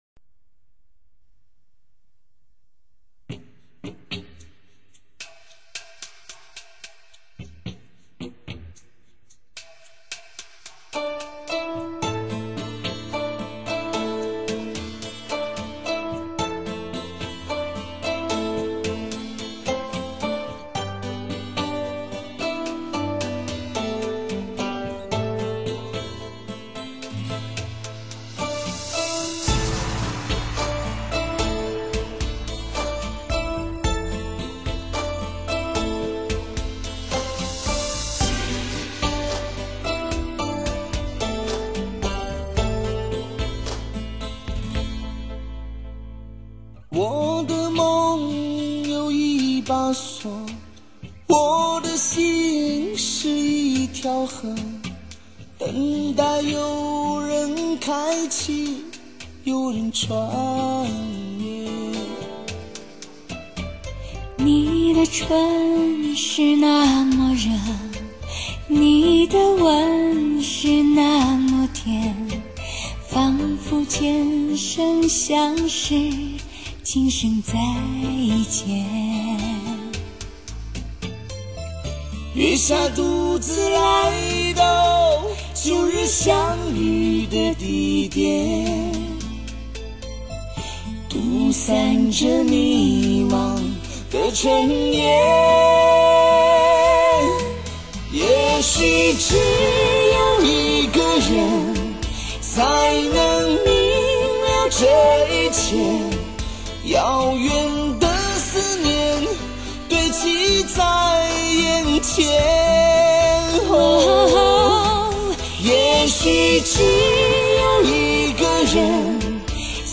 德国技术HD Master Audio实现完美监听效果。